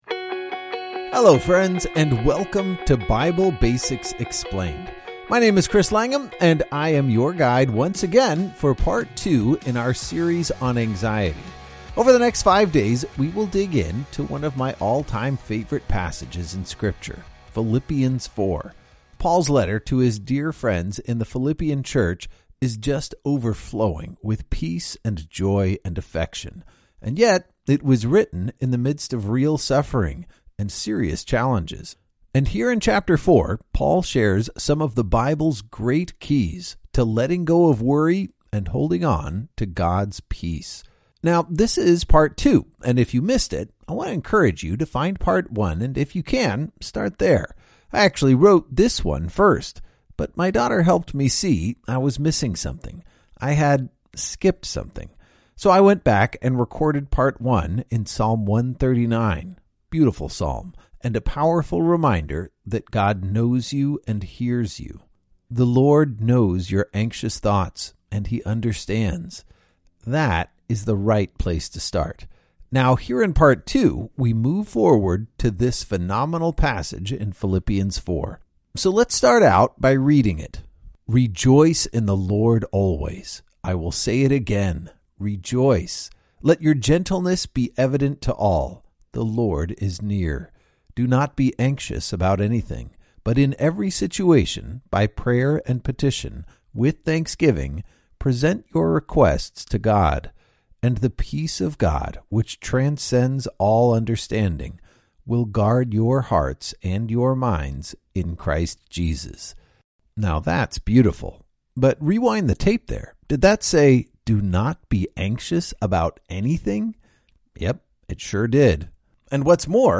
This devotional works best as an audio experience.